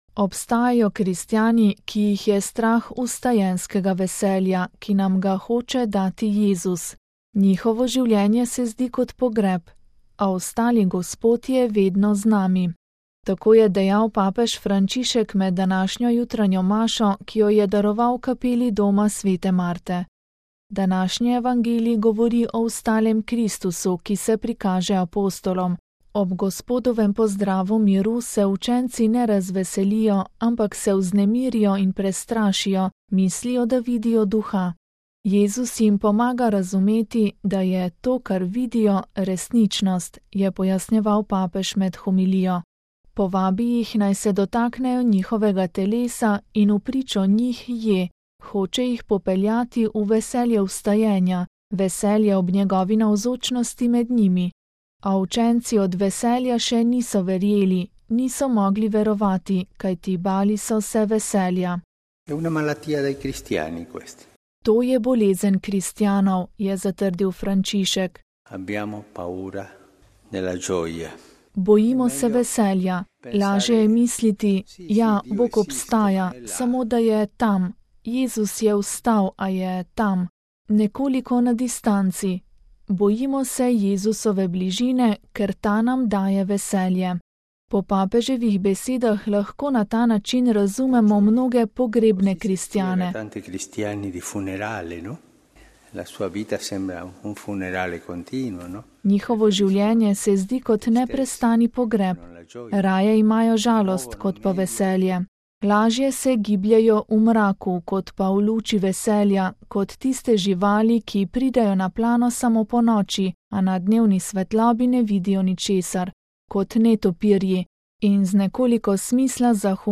Papež Frančišek med jutranjo homilijo: Ne bojmo se vstajenjskega veselja!
Tako je dejal papež Frančišek med današnjo jutranjo mašo, ki jo je daroval v kapeli Doma sv. Marte.